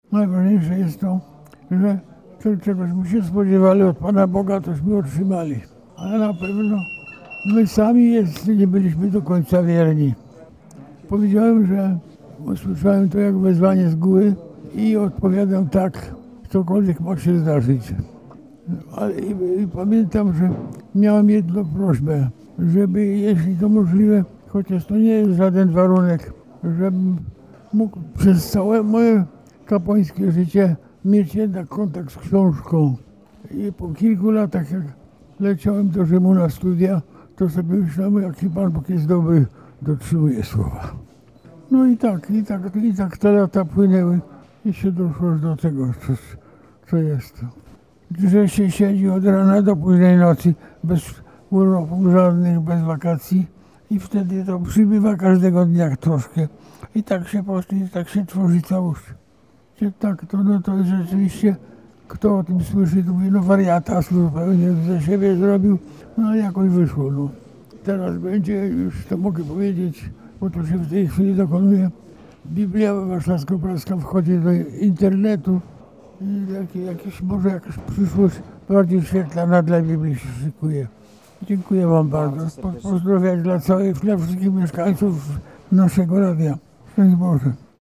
Przed Eucharystią biskup senior udzielił wywiadu Radiu Warszawa.
Poniżej wypowiedź biskupa Kazimierza Romaniuka dla Radia Warszawa z dnia 17.12.2021: